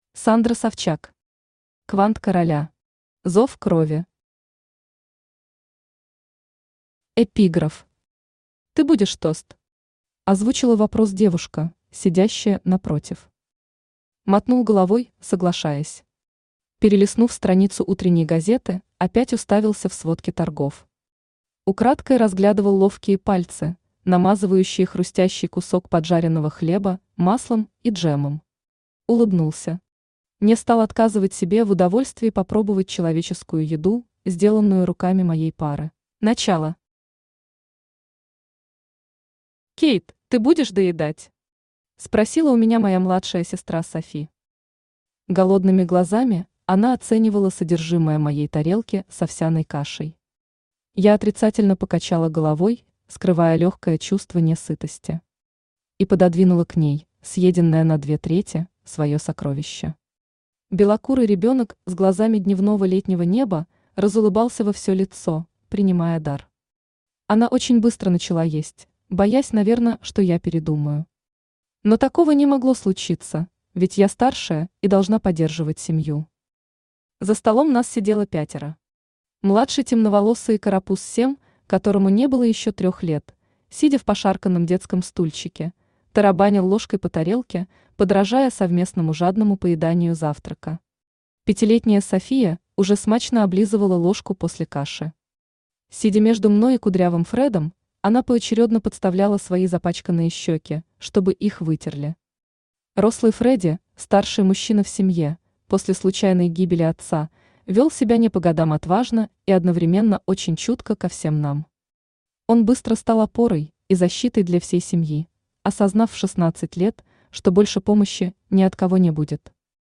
Аудиокнига Квант Короля.
Зов крови Автор Sandra Savchuk Читает аудиокнигу Авточтец ЛитРес.